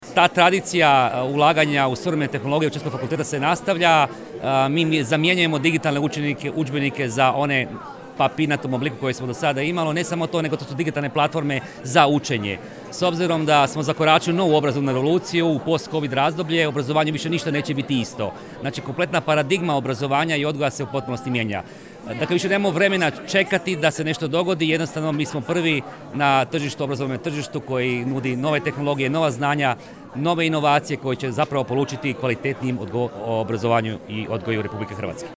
U nju je kroz javno-privatno partnerstvo uloženo 200 tisuća kuna i nastavak je ulaganja u digitalizaciju Učiteljskog fakulteta, moglo se čuti na prigodnoj svečanosti koja je bila i prilika za podjelu zahvalnica.